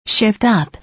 Attached a sample 'Shift Up' voice, what you think of it?